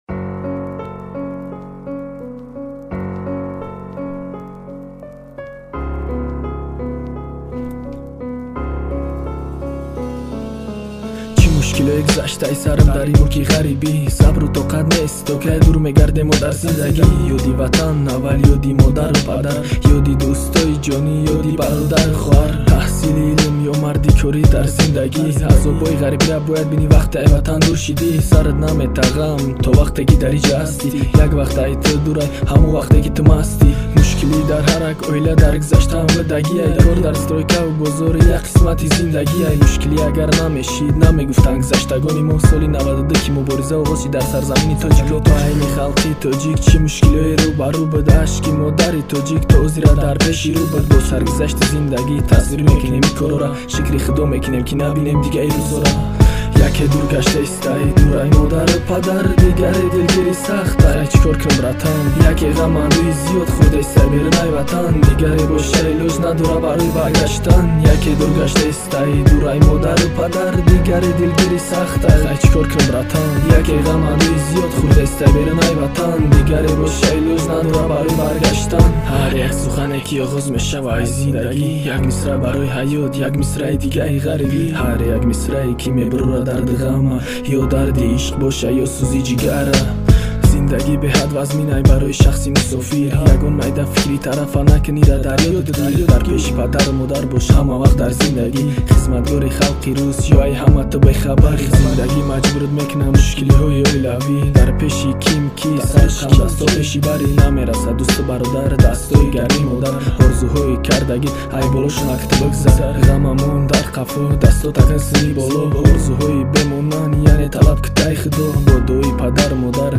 Главная » Файлы » Каталог Таджикских МР3 » Тадж. Rap
Категория: Тадж. Rap